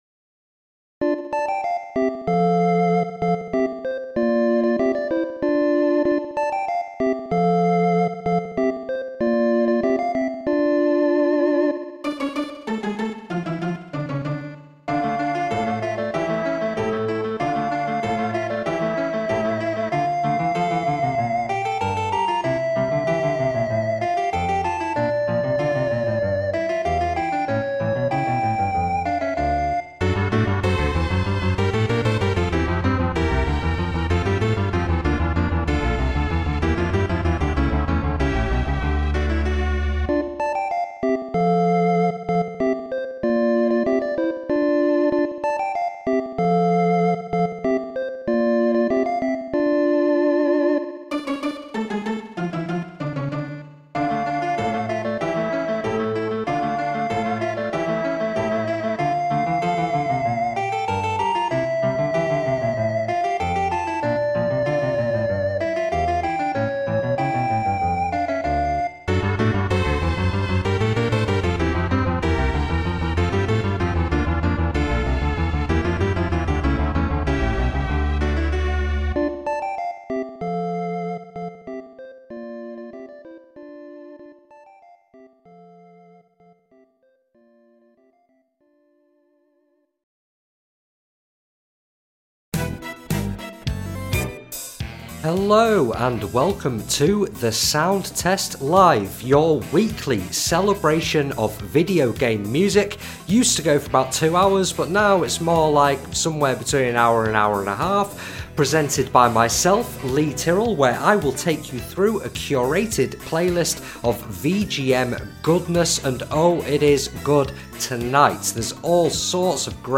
Weekly VGM Radio